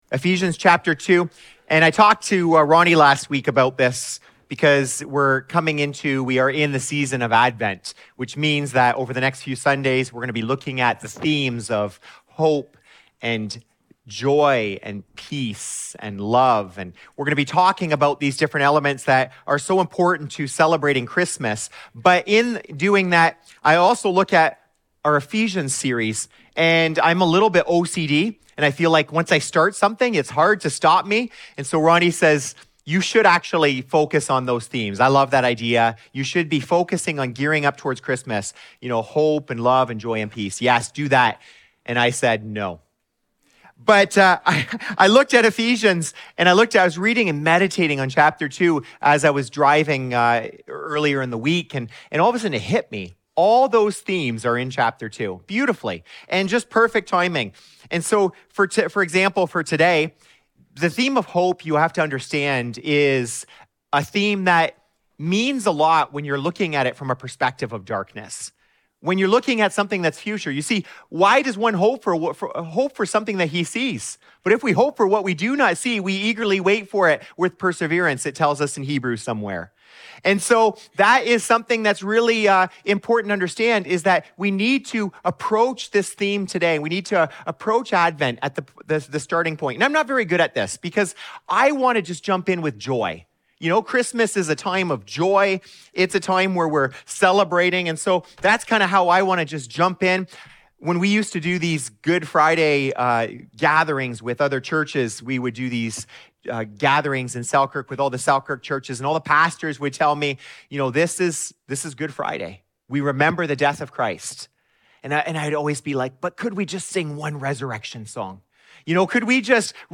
As the first candle of Advent—the candle of hope—burns, we reflect on the light that pierces the darkness through the birth of Jesus. This sermon unpacks the depth of our spiritual need and the transformative hope found in Christ. Exploring themes of spiritual deadness, enslavement to sin, and liberation through God's power, we are reminded that Jesus is the light of the world who brings life to the spiritually dead.